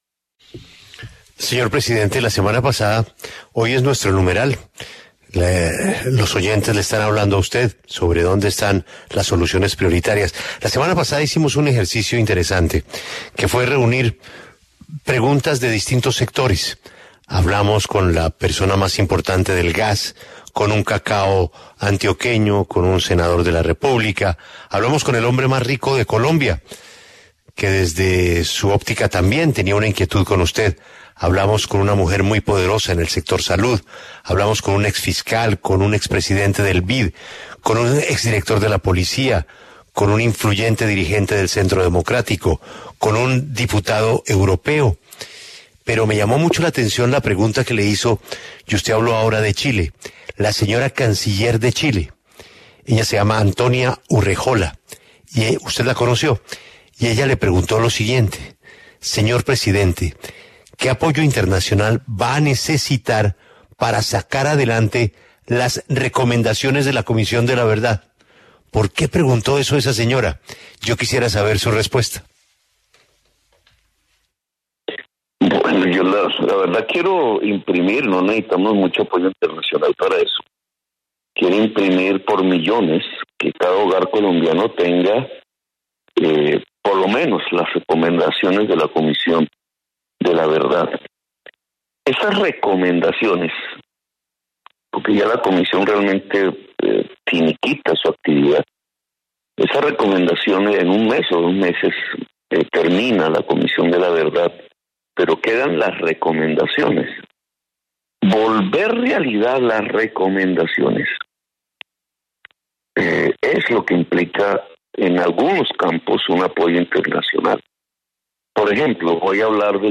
En diálogo con La W, el presidente electo Gustavo Petro respondió a la pregunta de Antonia Urrejola, canciller de Chile, sobre cómo va a sacar adelante las recomendaciones de la Comisión de la Verdad, sobre todo en busca del apoyo internacional.